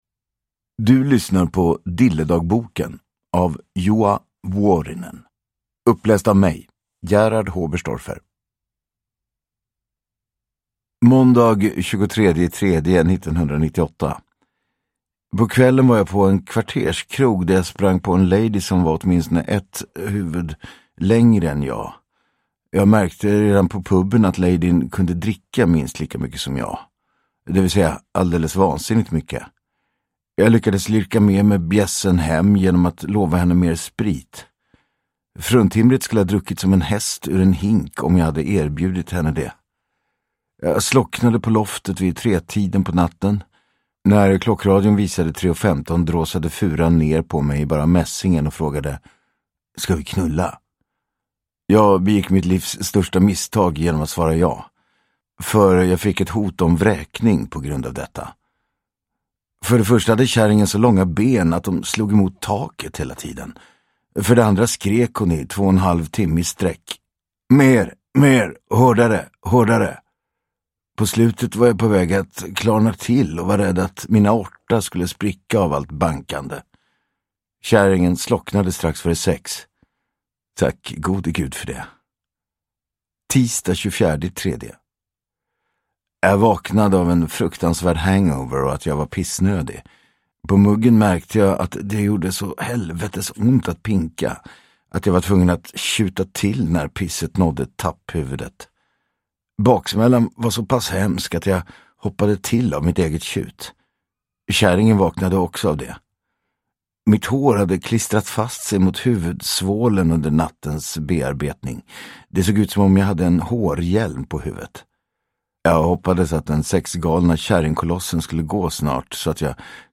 Dilledagboken – Ljudbok